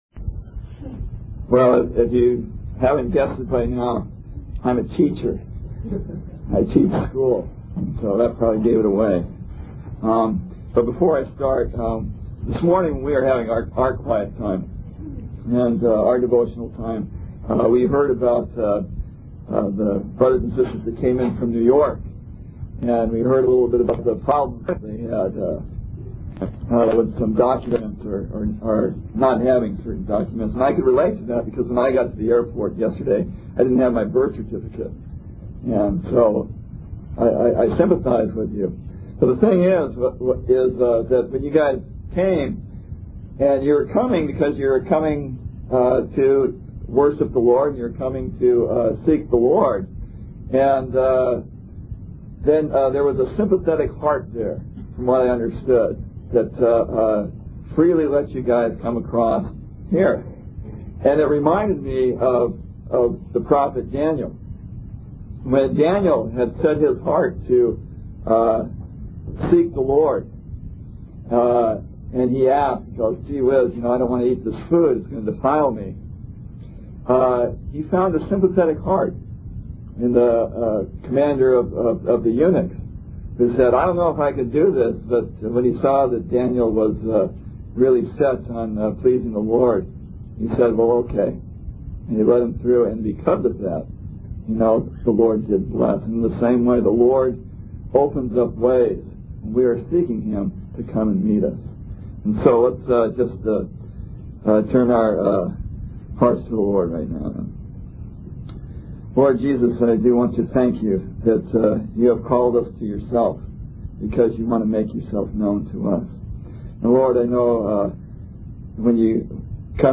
Toronto Summer Youth Conference